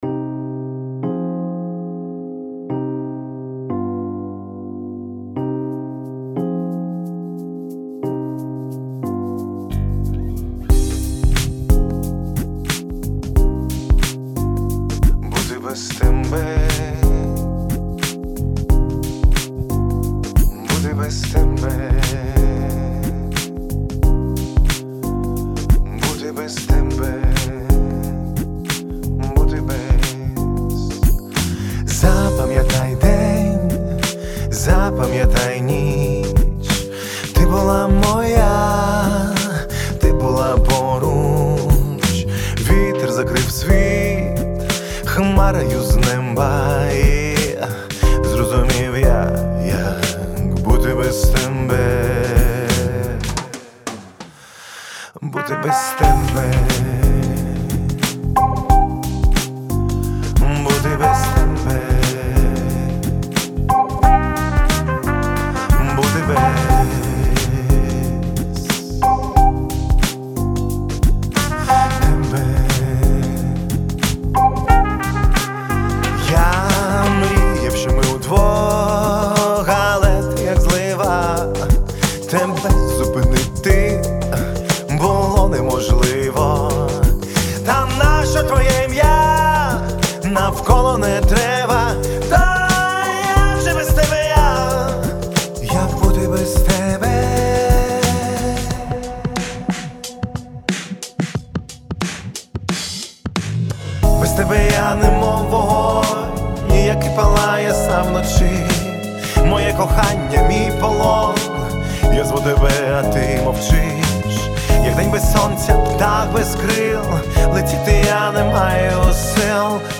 Вот решил полностью сделать на Авенджере попсовенькую песенку. Бас - Трилиан, труба Тайрос 5 остальное всё Авенджер.